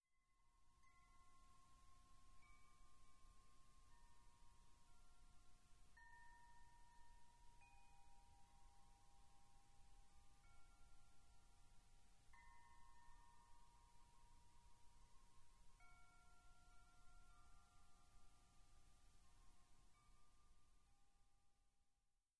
ModChimes produce beautiful, distinct, bell-like tones
C4 D4 F4 G4 A4